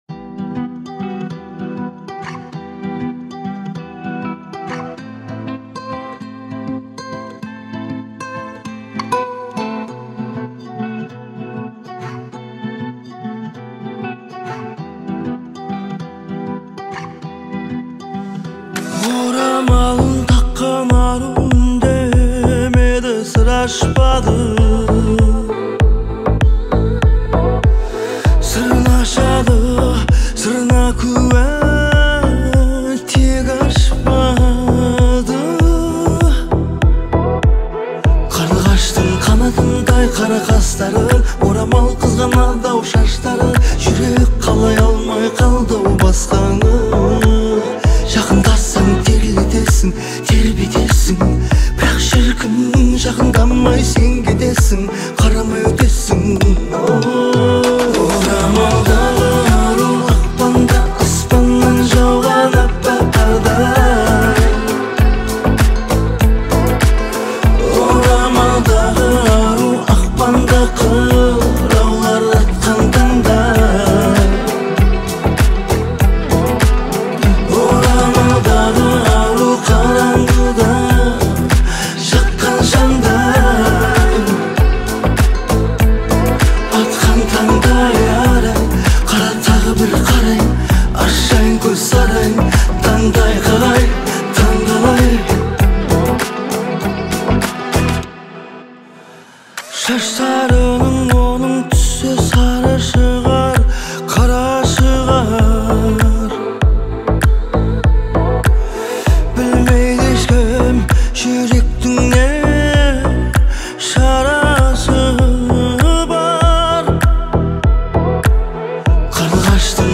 Казахская